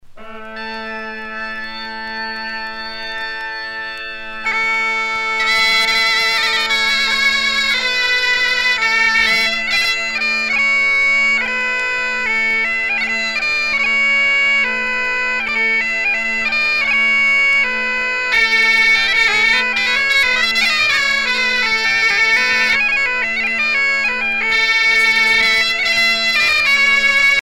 danse : bal (Bretagne)
Pièce musicale éditée